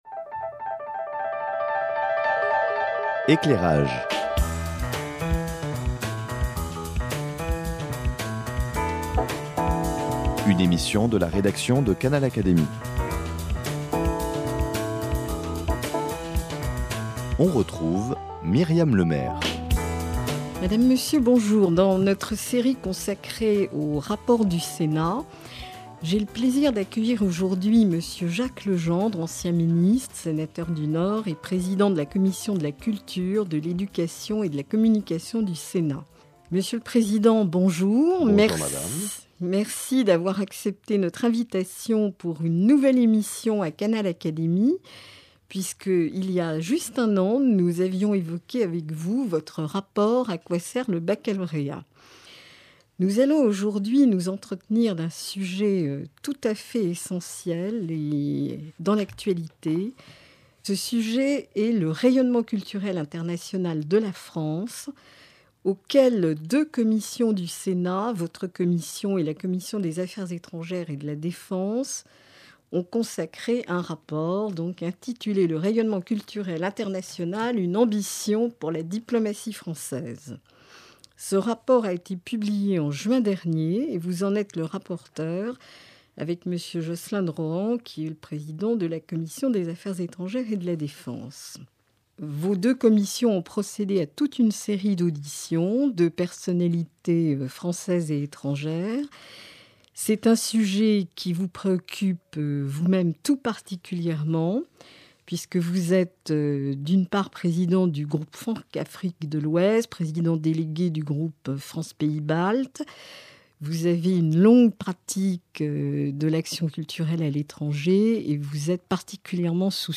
Le sénateur Jacques Legendre, Président de la commission des affaires culturelles, nous dit l’importance de la diplomatie culturelle pour notre pays, les difficultés qu’elle traverse, et évoque les propositions du Sénat pour relancer l’action culturelle extérieure de la France.
Cette émission a été diffusée en novembre dernier